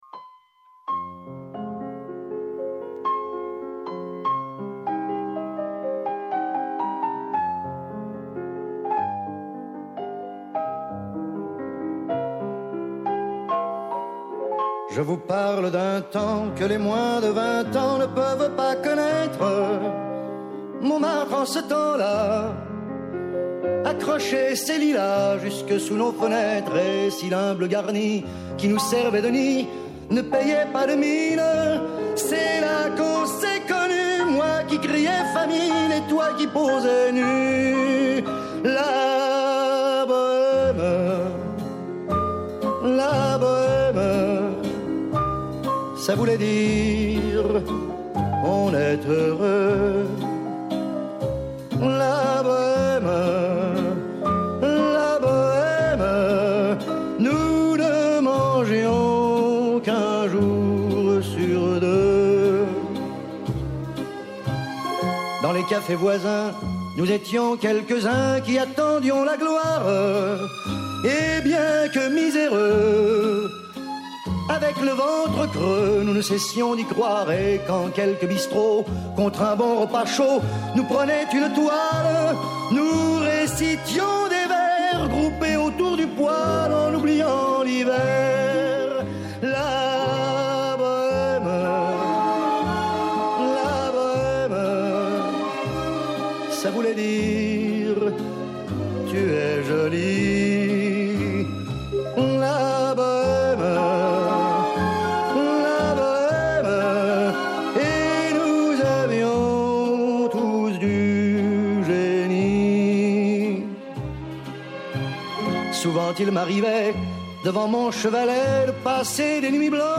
Απόψε, στο Doc On Air μας μιλούν :